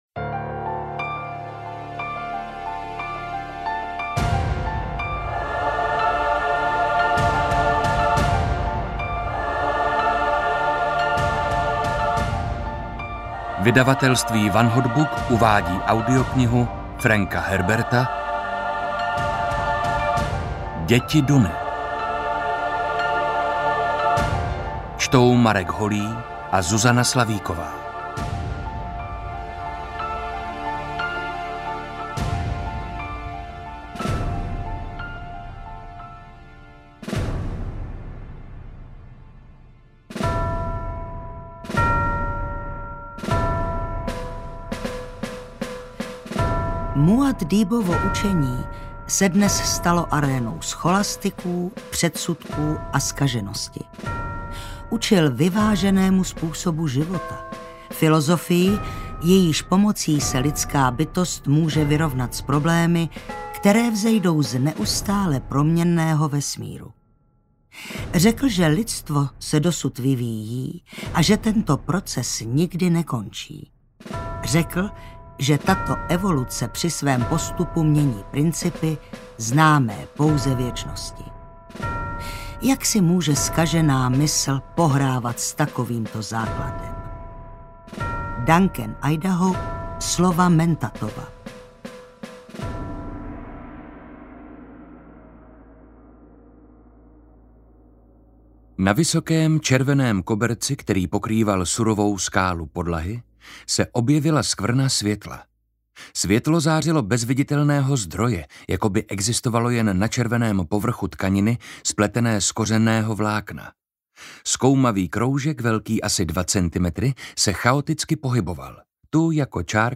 AudioKniha ke stažení, 64 x mp3, délka 18 hod. 30 min., velikost 1007,0 MB, česky